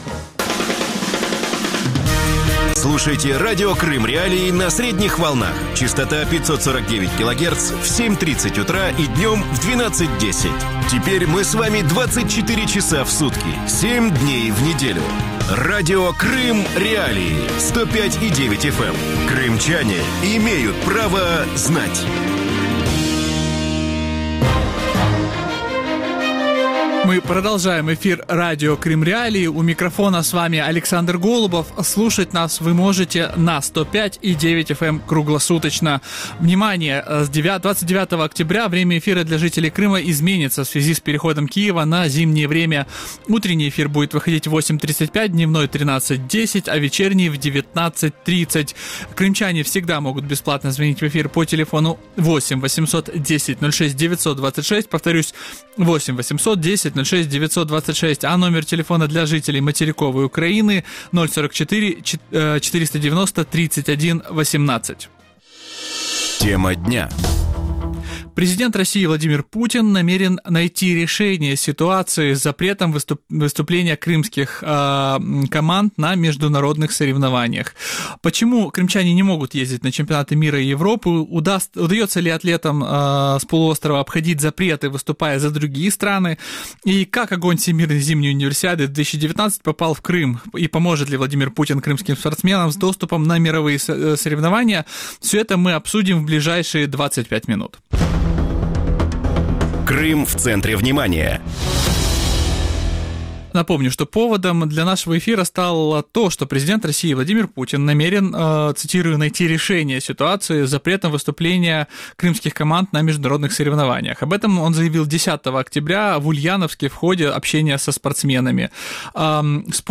российский спортивный журналист